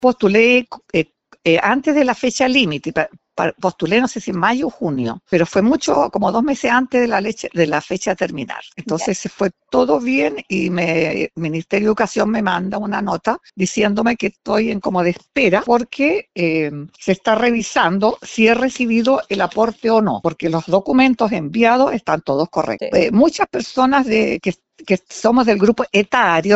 04-11-profesora-afectada.mp3